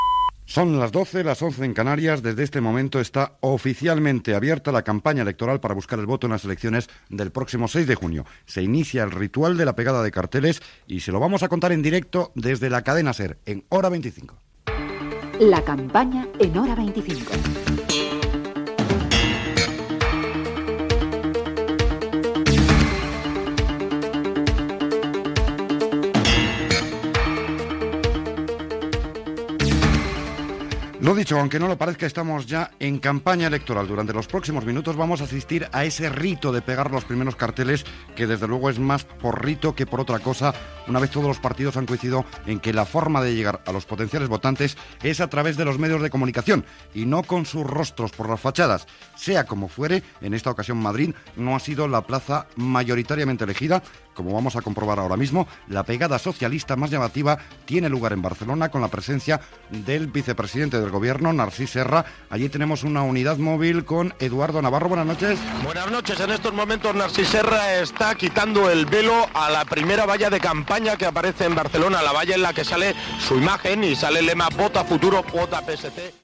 Informatiu especial "La campaña en hora 25" la nit de l'inici de la campanya dels partits polítics que es presentaven a les eleccions generals espanyoles.
Informatiu